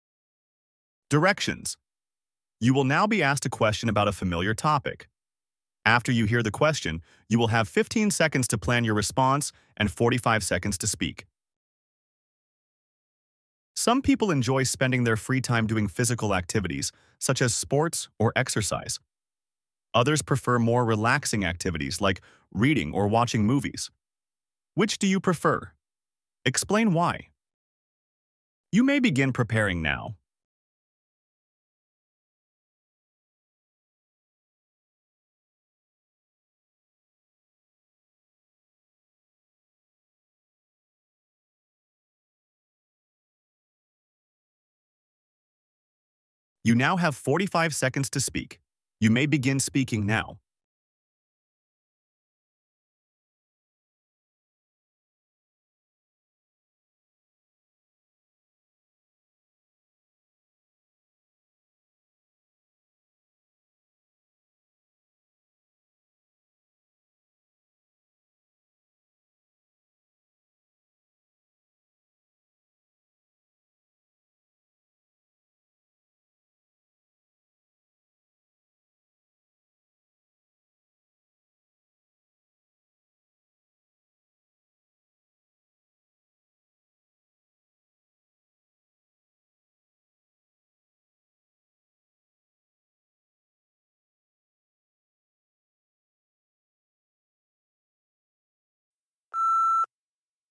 toefl-speaking-question-1-donation-3-directions-and-questions.mp3